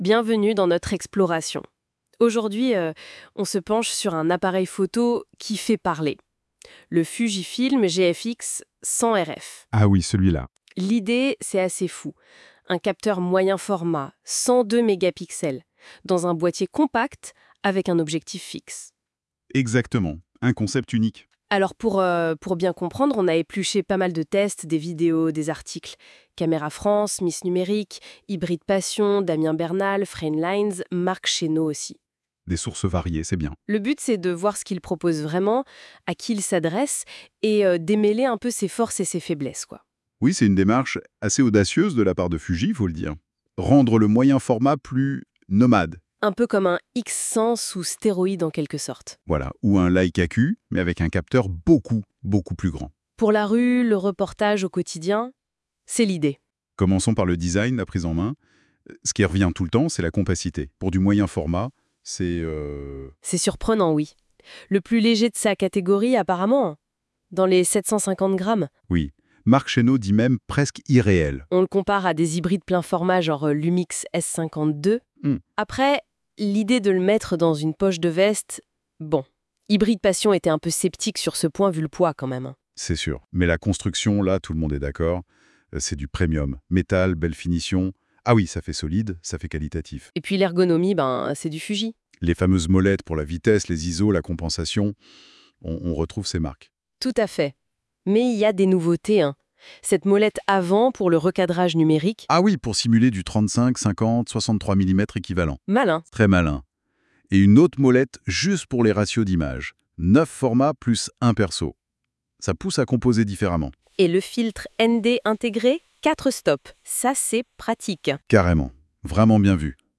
Ecouter le podcast IA qui résume tout l’intérêt du GFX100RF